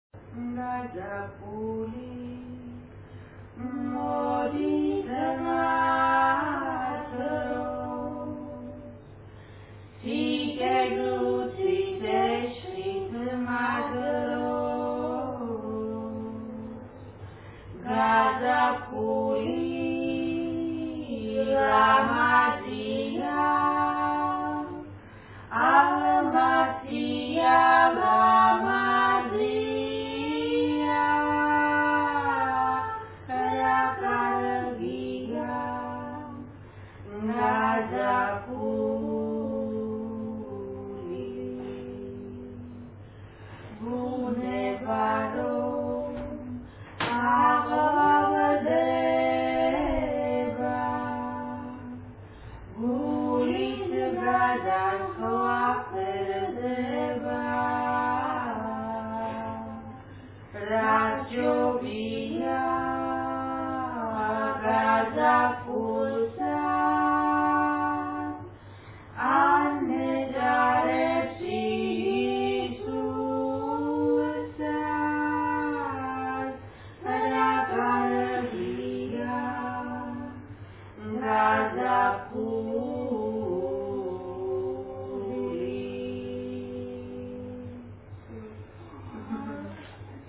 Kalakuri simghera often have piano or guitar accompaniment; theirs is a very romantic genre, both in lyrical and musical content, and they adhere strictly to the Western tonal system though employing Georgian lyrics and turns of melodic phrase.
when we were finishing up eating together after a Georgian lesson.